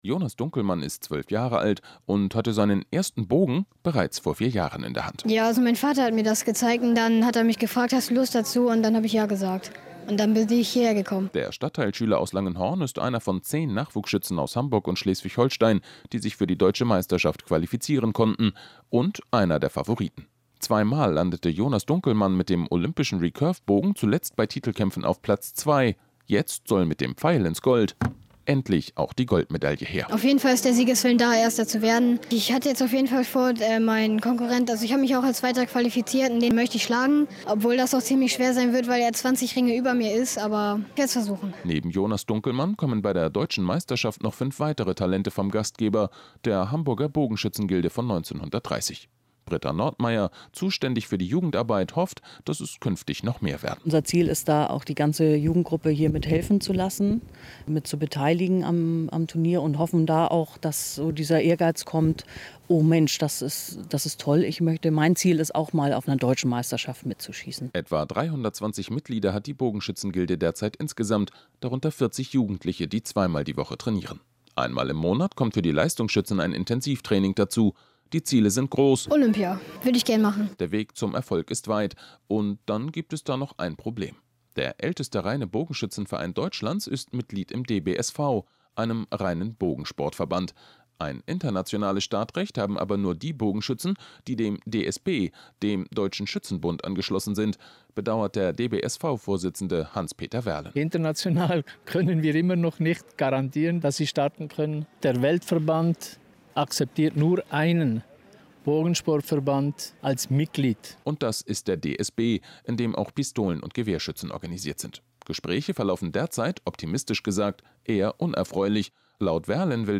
Im Radio
Der NDR 90,3 hat einen kleinen Beitrag gebracht, und ein wenig Humor gestreut.